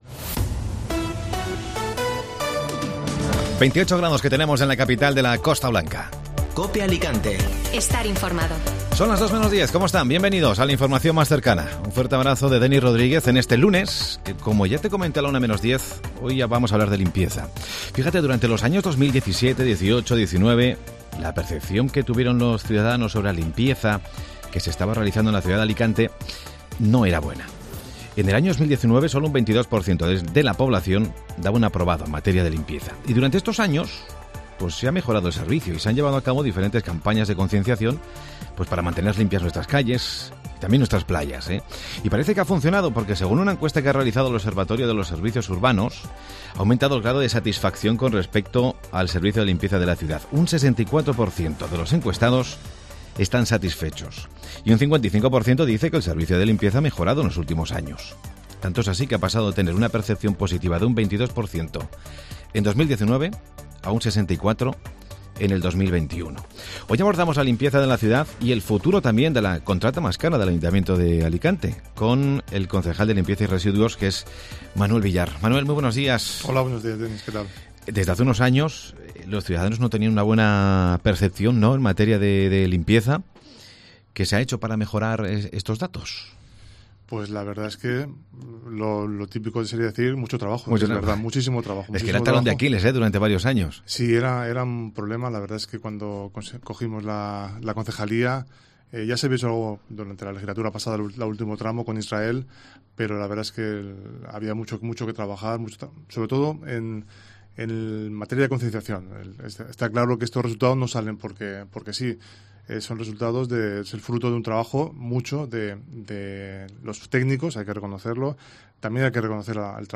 AUDIO: Manuel Villar, concejal de Limpieza y Residuos habla sobre la nueva licitación de limpieza de Alicante